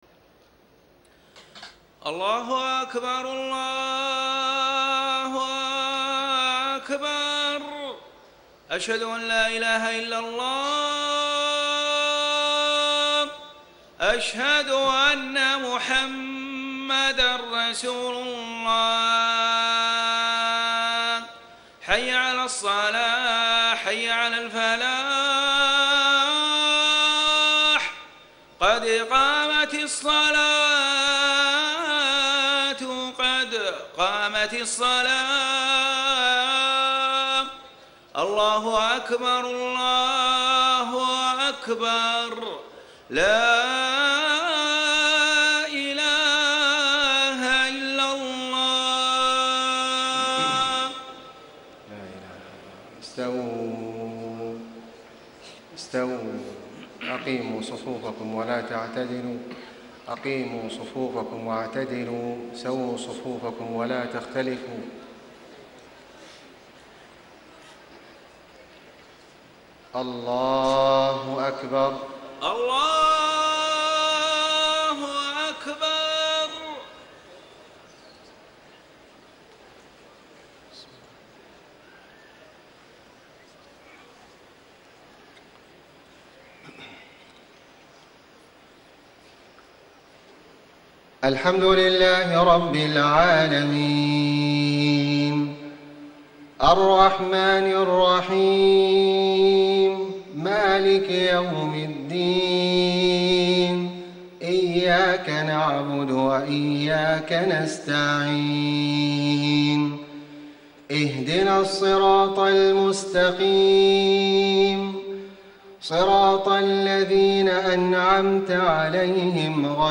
صلاة العشاء 2-5-1435 سورة محمد > 1435 🕋 > الفروض - تلاوات الحرمين